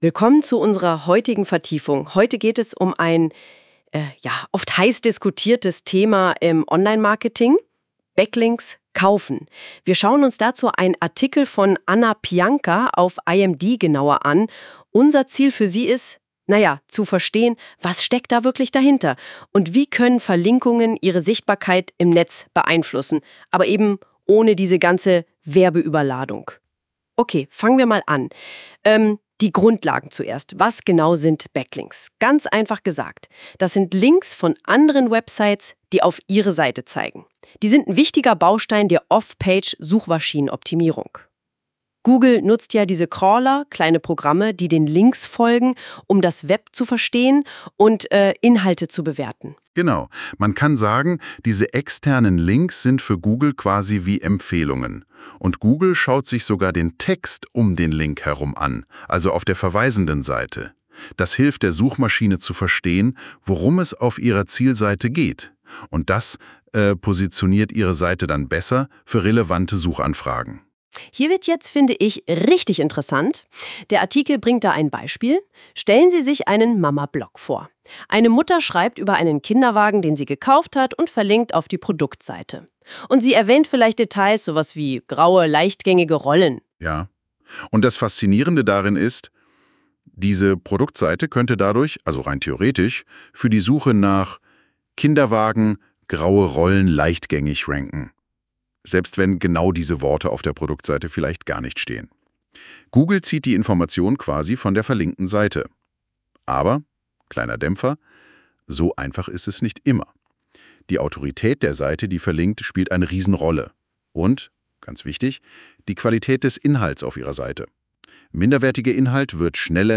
Dann diskutieren KI-generierte Stimmen die wichtigsten Inhalte der bereitgestellten Quellen. Vereinzelt kann es zu Audiostörungen oder Fehlern kommen, im Großen und Ganzen klingt eine solche Audio-Zusammenfassung recht natürlich.
Die Aussprache der Wörter und die Wiedergabe des Inhalts als zusammenfassendes Gespräch zwischen zwei Personen sind korrekt erfolgt.